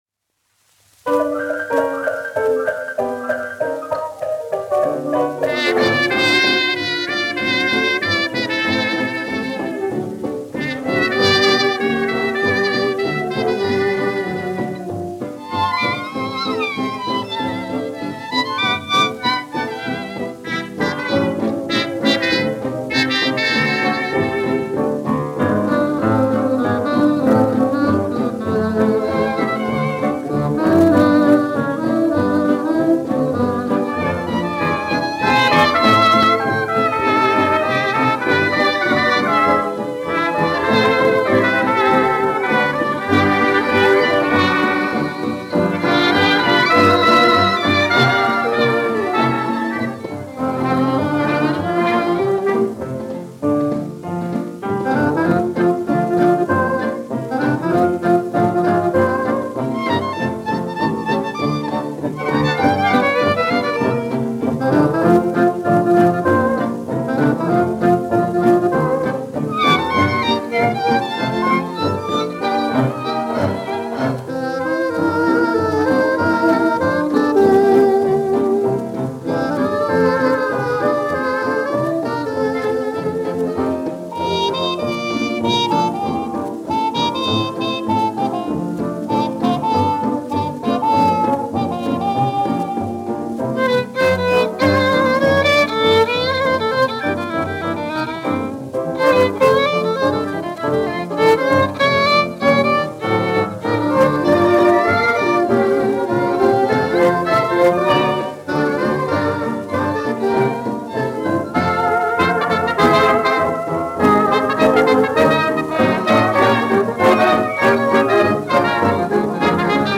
1 skpl. : analogs, 78 apgr/min, mono ; 25 cm
Fokstroti
Populārā instrumentālā mūzika
Latvijas vēsturiskie šellaka skaņuplašu ieraksti (Kolekcija)